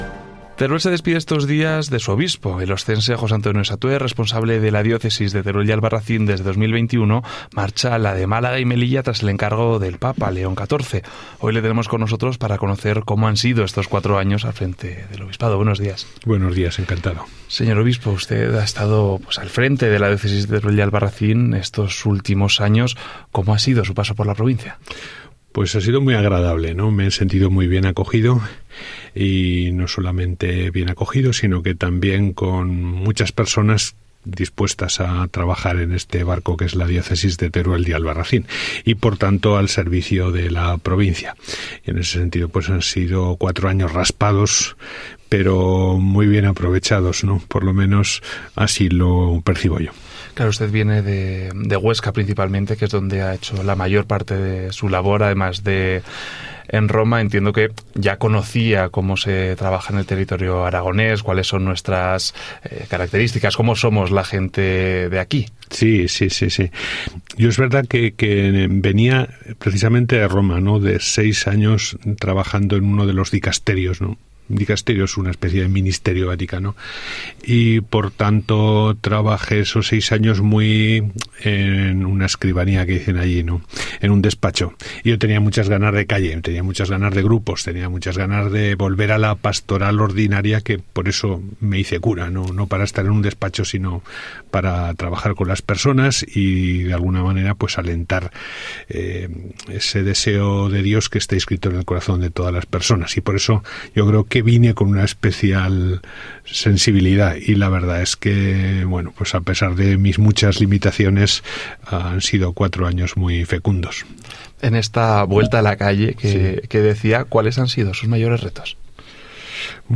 En los micrófonos de RNE fue entrevistado para hacer balance de los 4 años que ha estado al frente de nuestra diócesis, donde, en palabras de don José Antonio,  han sido cuatro años «muy agradables y me he sentido bien acogido».
entrevistaRNE26agosto25Satue.mp3